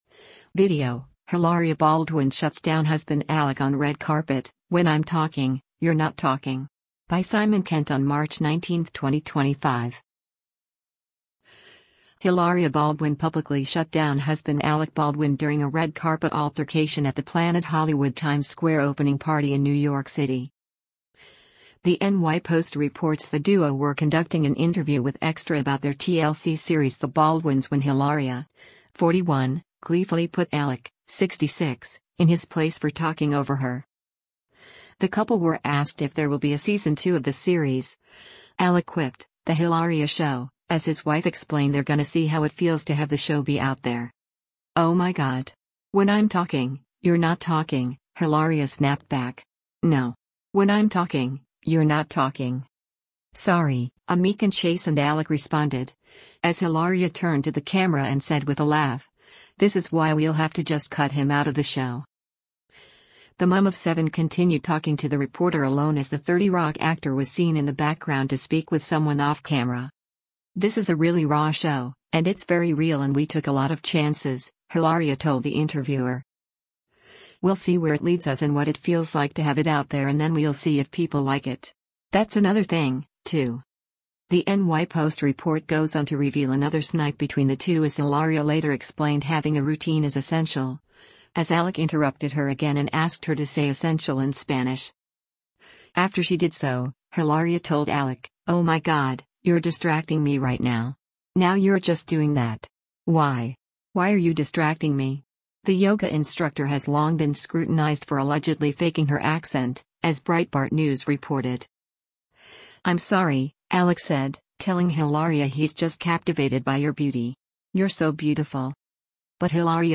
Hilaria Baldwin publicly shut down husband Alec Baldwin during a red carpet altercation at the Planet Hollywood Times Square opening party in New York City.
“Sorry,” a meek and chastened Alec responded, as Hilaria turned to the camera and said with a laugh, “This is why we’ll have to just cut him out of the show.”
Alec then smiled and walked away in the background as Hilaria finished the interview.